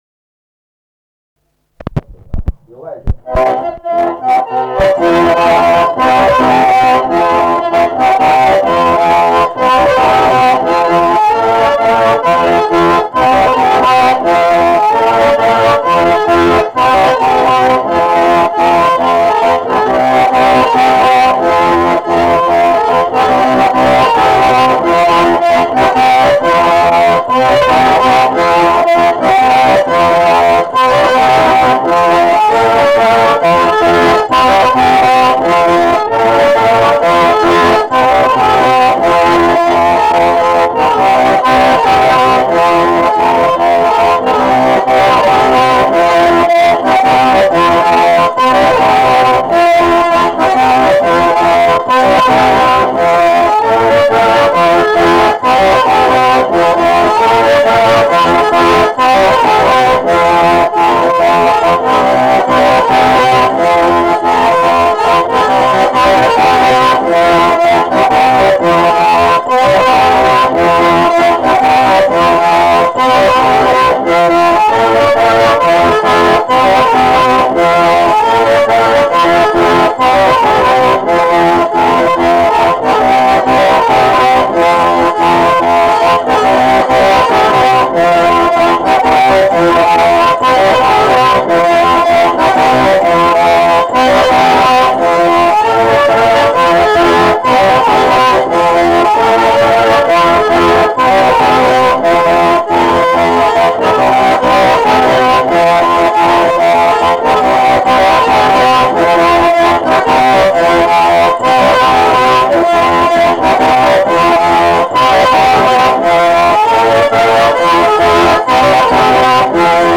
šokis